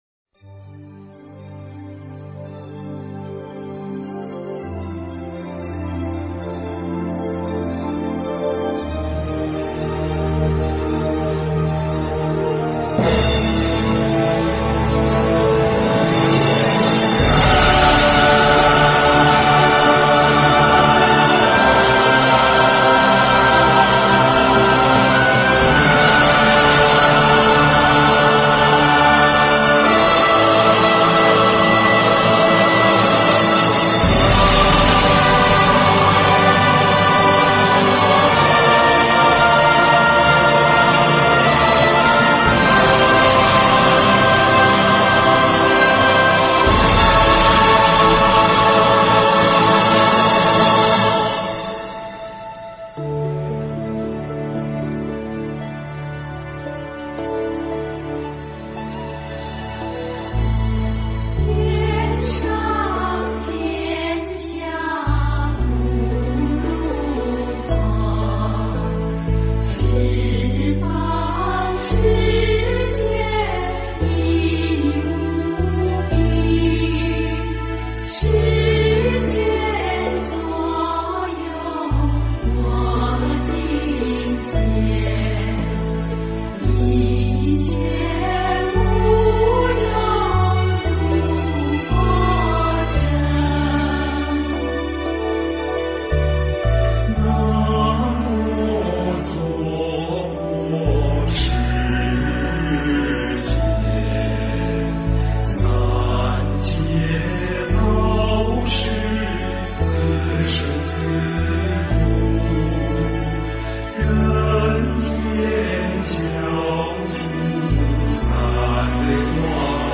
标签: 佛音经忏佛教音乐